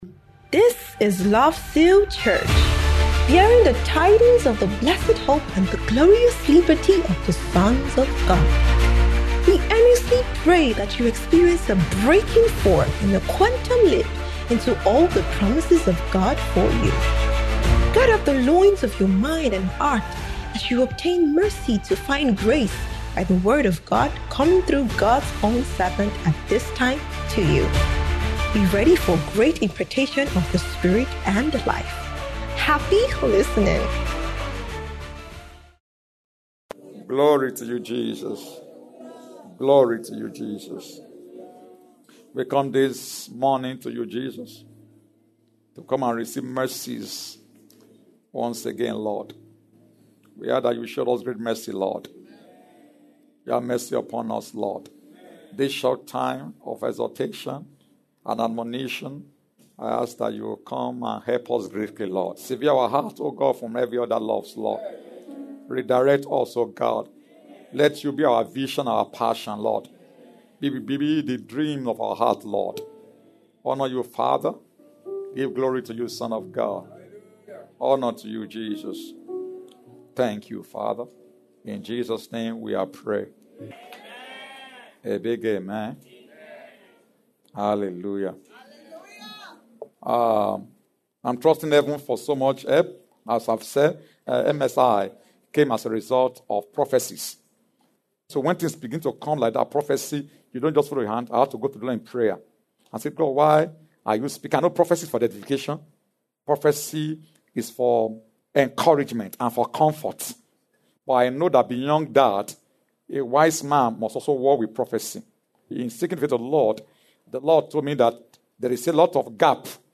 Marriageable Singles’ Ingathering